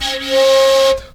FLUTELIN06.wav